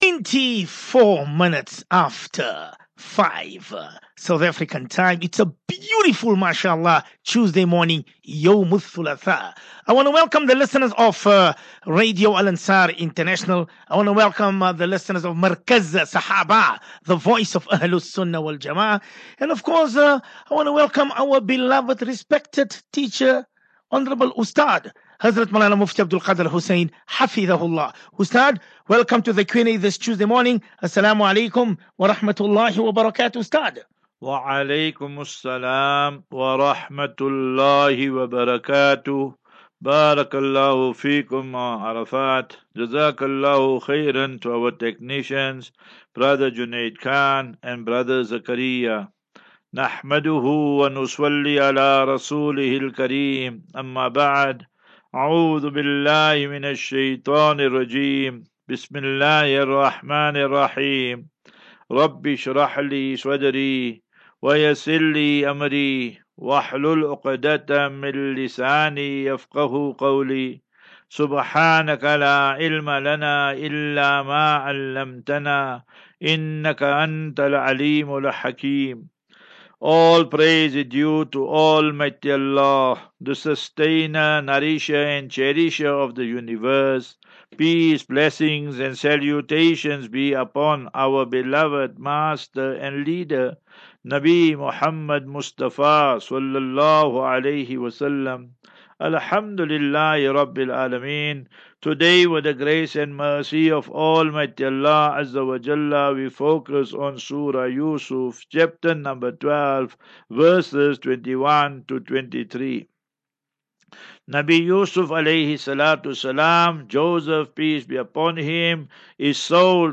View Promo Continue Install As Safinatu Ilal Jannah Naseeha and Q and A 28 Mar 28 Mar 23 Assafinatu-Illal-Jannah 36 MIN Download